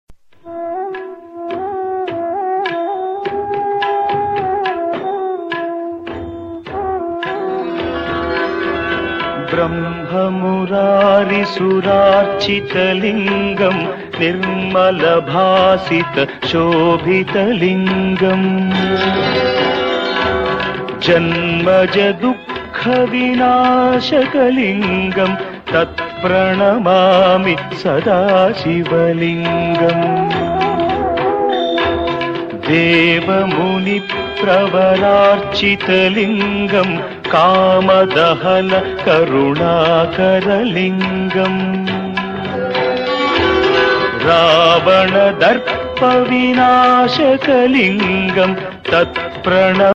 3_AAC_aac-lc_7350Hz_mono.aac